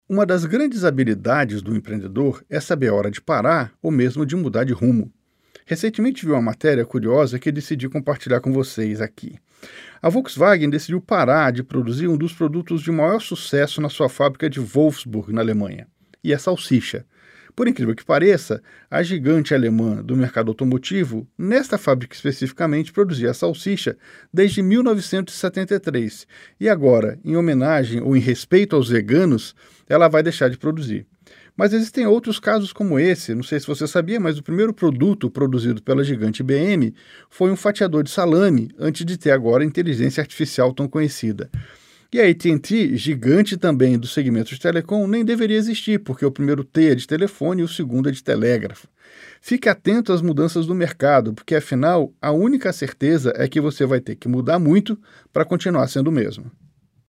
Categoria: Coluna
Periodicidade: 5 vezes por semana (segunda a sexta-feira), gravada